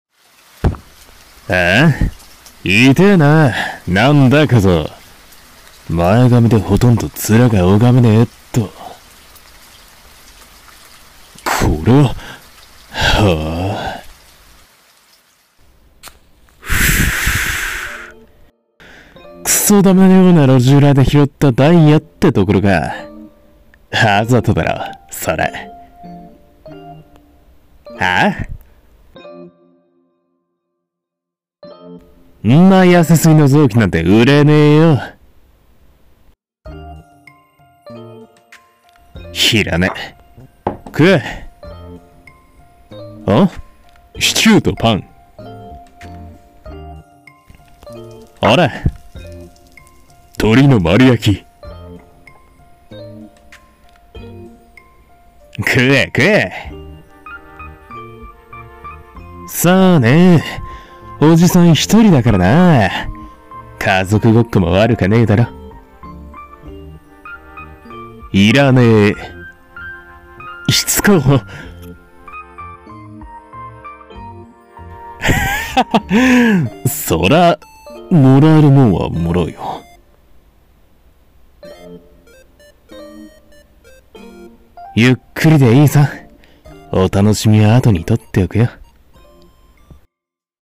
最高のフルコースを 【 2人声劇 】